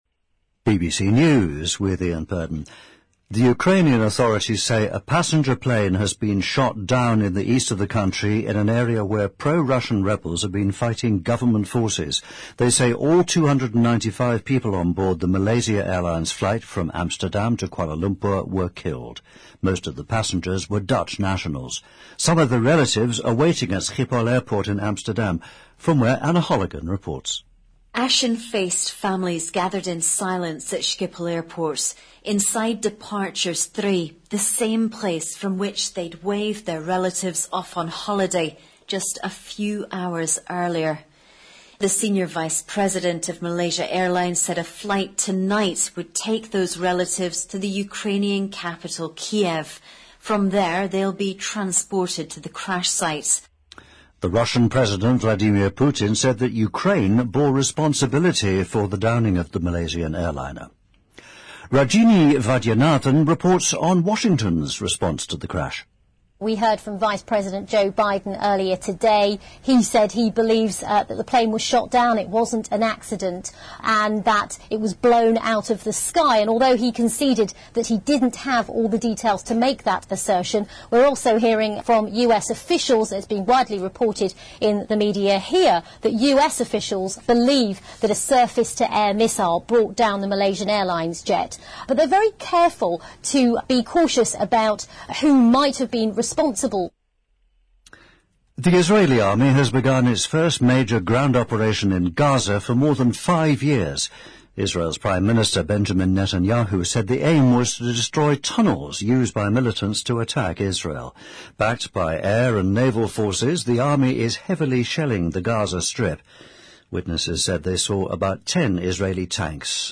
BBC news,美国女演员兼歌手伊莲娜·斯屈奇去世，享年89岁